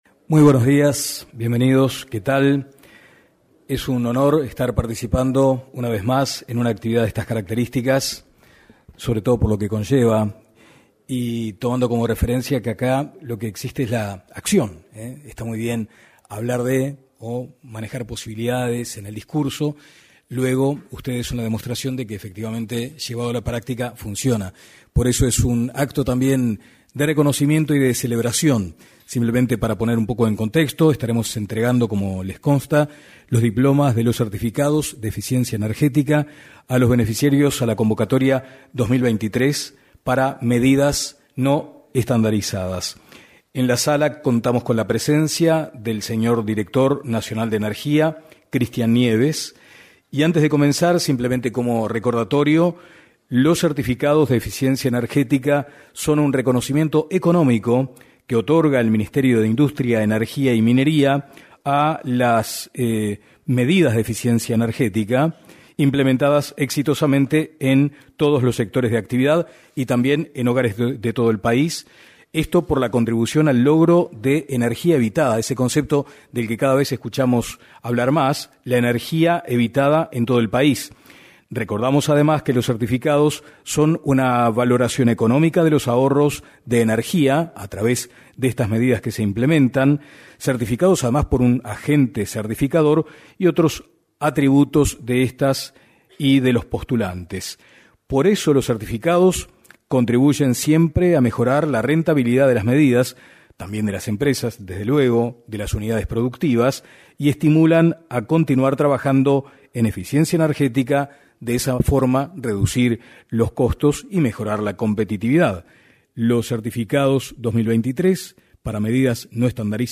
En la oportunidad, se expresaron el director nacional de Energía, Christian Nieves, y el director del Área de Eficiencia Energética, Sebastián Latanzzio.